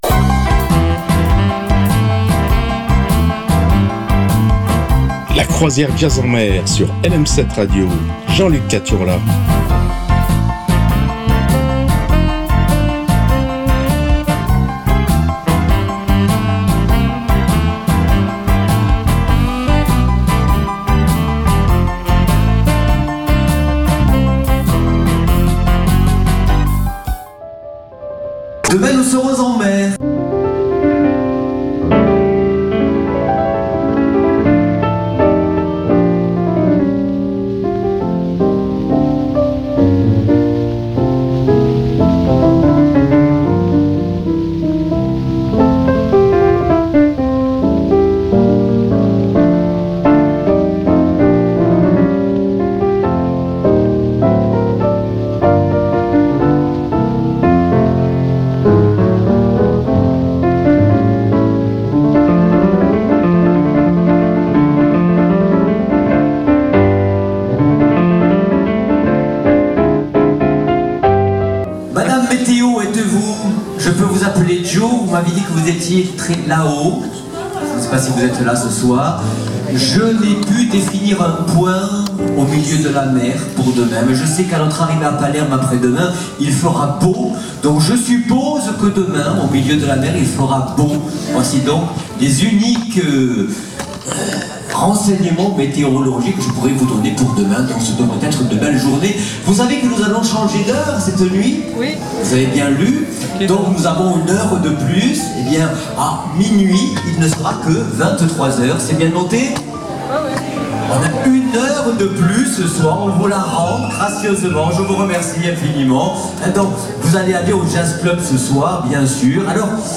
passer une demi-heure sur des rythmes jazzy